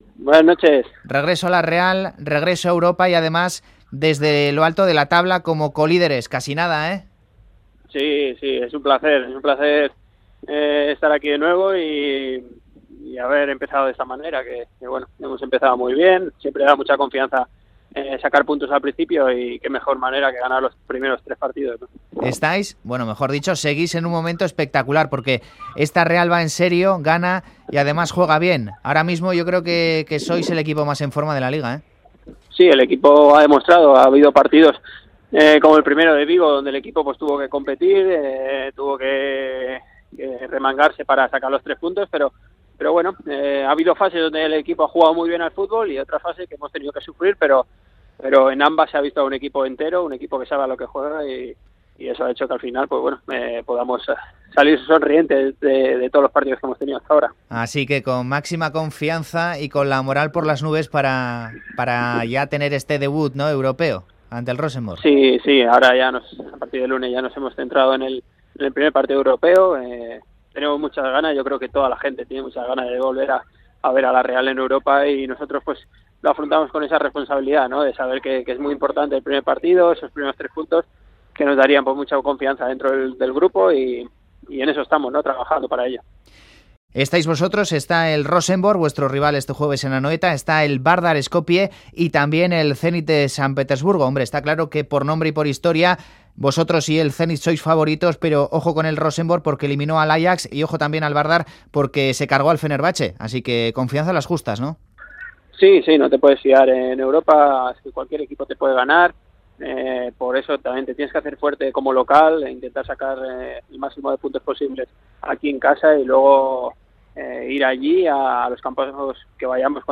Audio: El lateral catalán atiende la llamada de Fuera de Juego antes de comenzar la Europa League este jueves ante el Rosenborg en Anoeta.